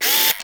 CAMERA_DSLR_AutoFocus_Motor_02_mono.wav